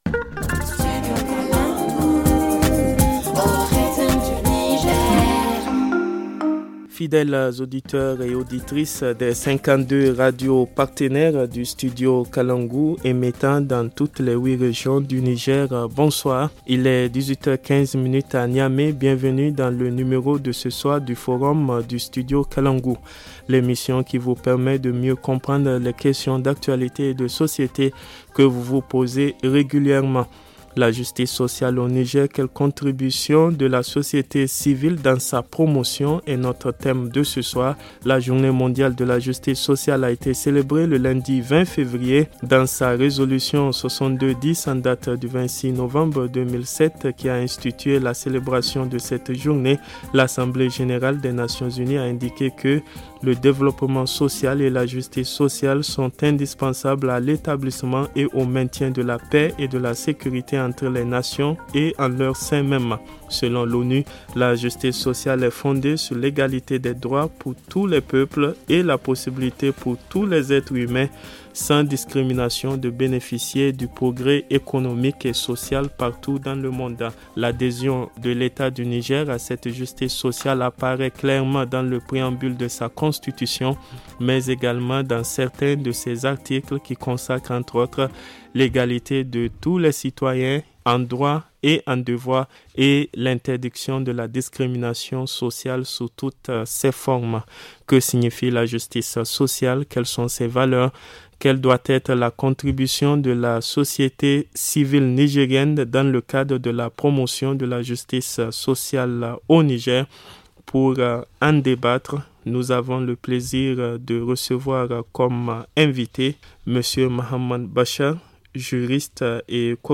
[Rediffusion] La justice sociale au Niger : Quelle contribution de la société civile dans sa promotion ?